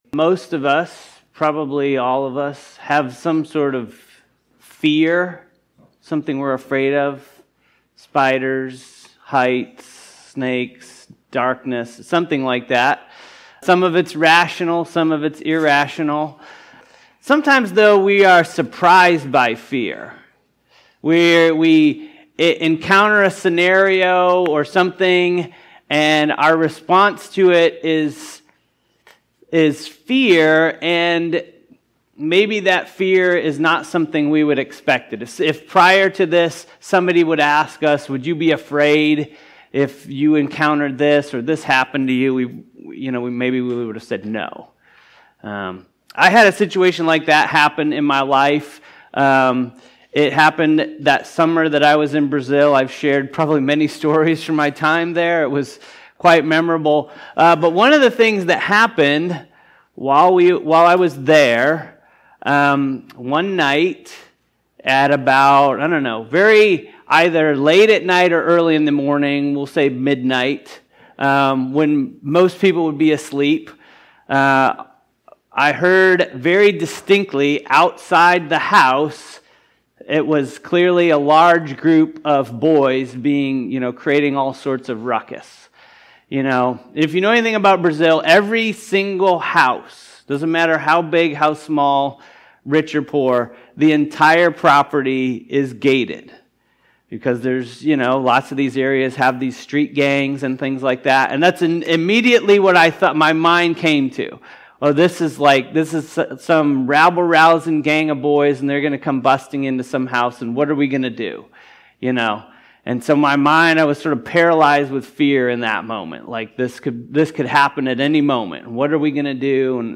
Sermon on Mark 14:43-72: Overcoming Fear with Jesus’ Grace and Forgiveness